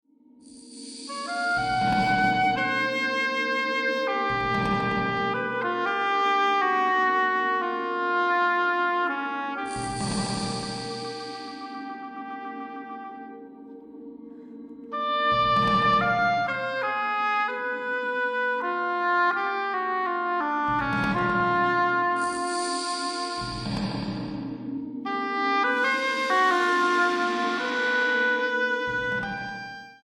oboe y cinta